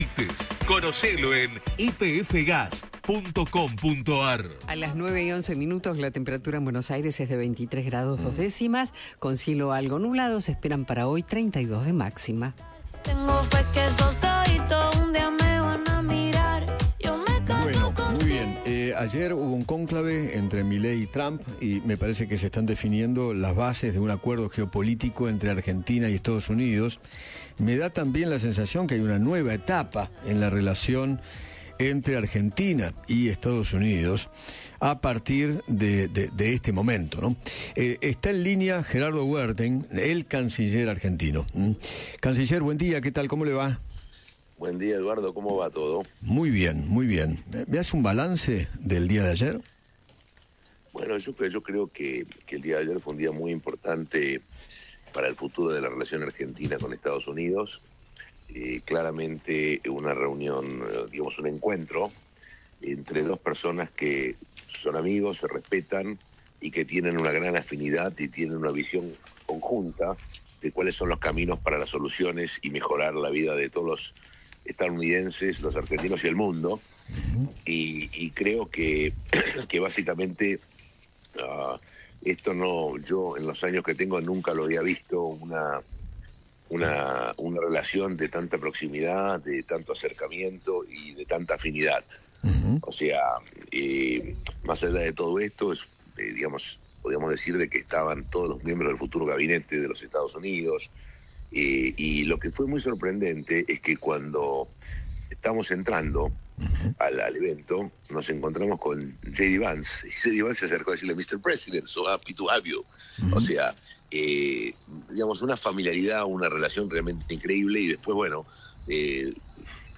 Gerardo Werthein, flamante canciller de Argentina, habló con Eduardo Feinmann sobre cómo fue el encuentro entre Javier Milei y Donald Trump y reveló de qué charlaron.